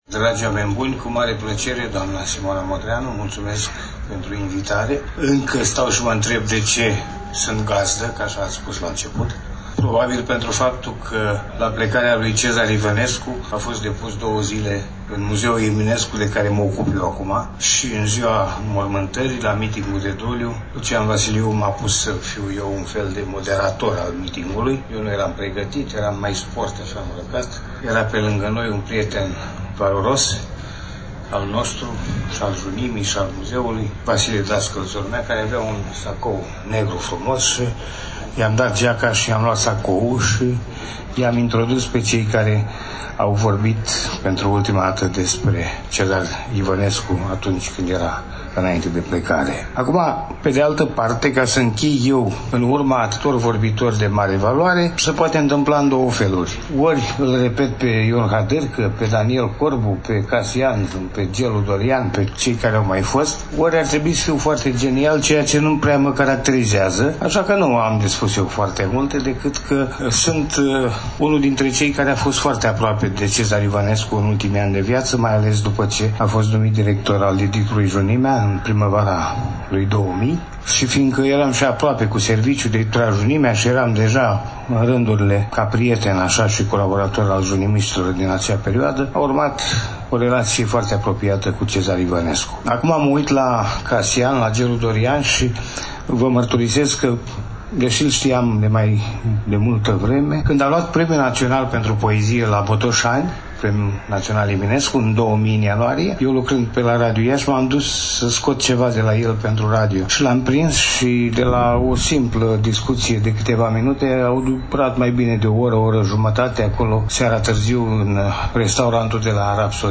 Dragi prieteni, după cum bine știți, în ediția de astăzi a emisiunii noatsre, relatăm de la prima ediție a manifestării anuale – „Întâlnirile Cezar IVĂNESCU”, eveniment desfășurat, la Iași, în luna aprilie a acestui an, cu prilejul împlinirii a 15 ani de la plecarea „dincolo” a poetului.